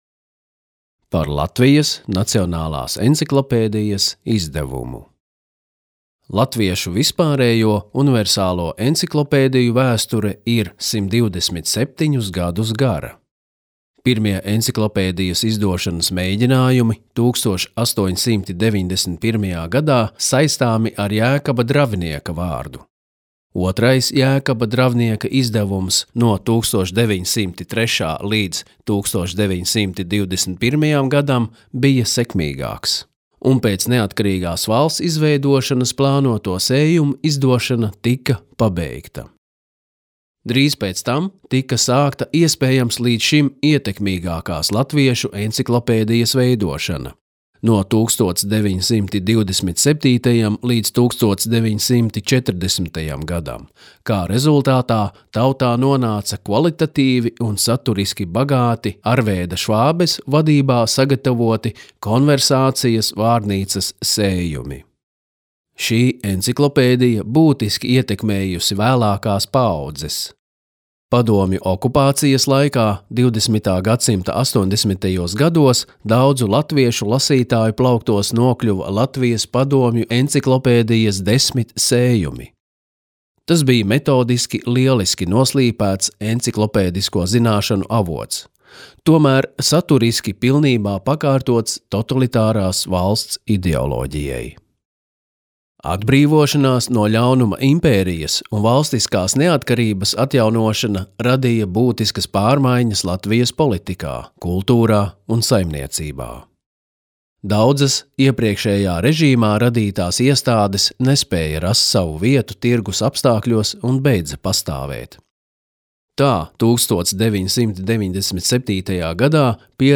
2019. gadā, atzīmējot Nacionālās enciklopēdijas drukātā sējuma "Latvija" (2018) pirmo gadadienu, Latvijas Nacionālās bibliotēkas un Latvijas Radio darbinieki sagatavoja Nacionālās enciklopēdijas drukātā sējuma šķirkļu audio ierakstus.
Latvijas Nacionālās bibliotēkas audio studijas ieraksti (Kolekcija)